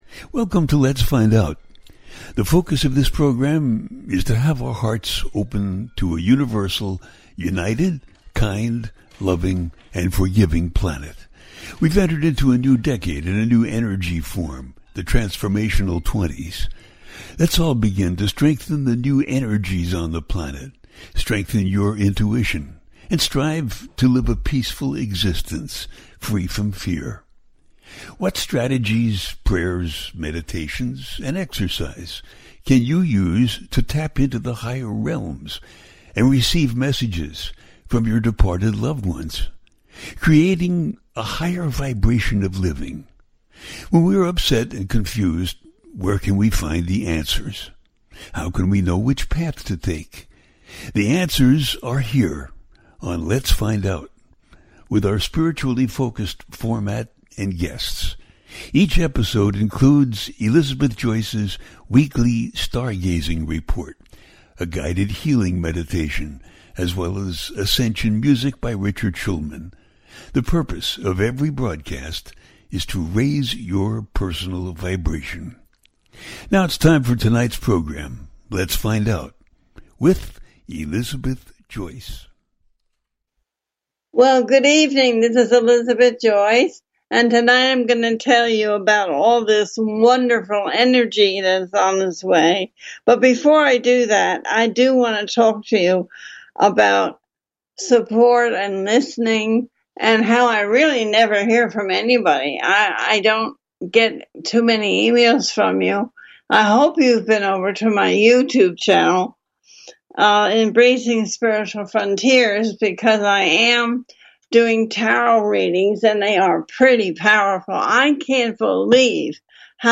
Talk Show Episode
The listener can call in to ask a question on the air.
Each show ends with a guided meditation.